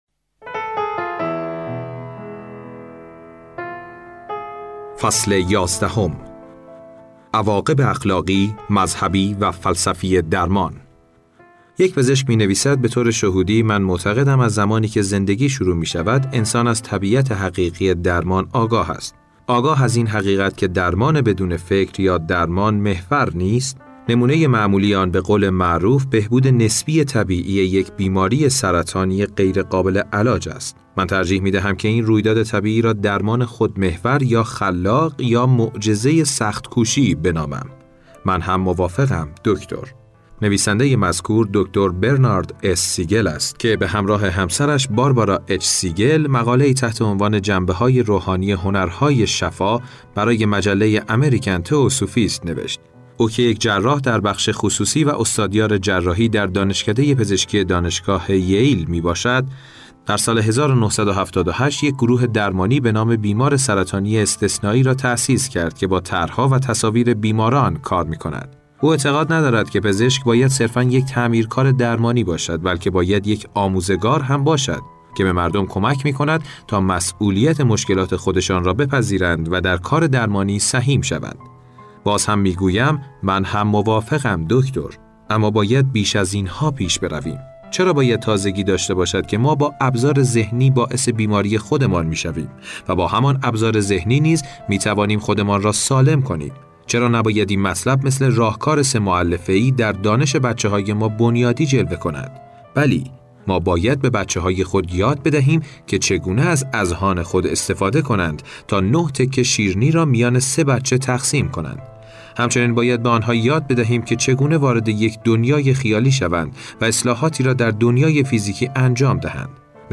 گل مهدی طارمی برای المپیاکوس در جام حذفی یونان / فیلم برچسب‌ها: کتاب صوتی دستان شفا بخش نویسنده خوزه سیلوا دیدگاه‌ها (اولین دیدگاه را بنویسید) برای ارسال دیدگاه وارد شوید.